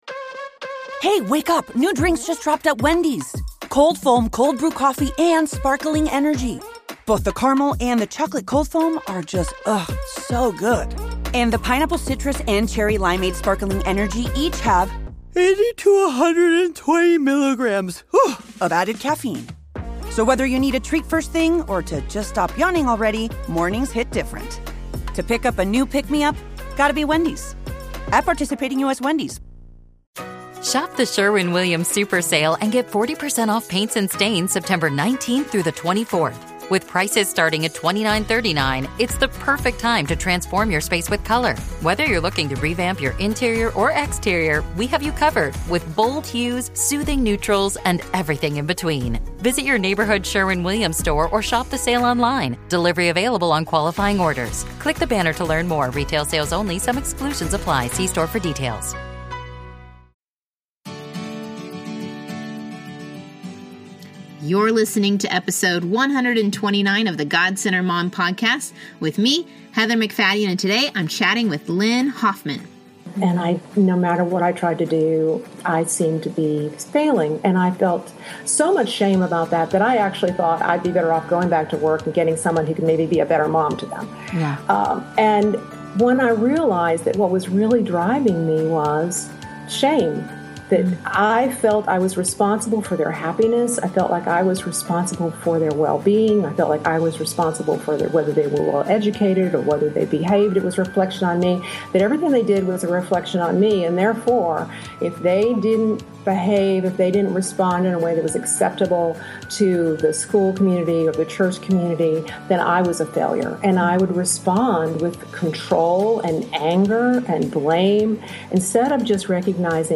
interviews guests discussing the topic of staying God-centered...both replacing "me" with "He" and remembering we are centered in Him.